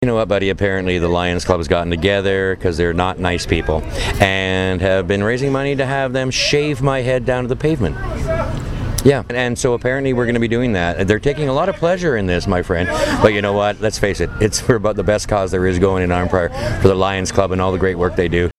The Third Annual Arnprior Lions Jail and Bail had more comedy and drama than ever before, as the fundraiser nestled into the Giant Tiger Parking Lot Saturday.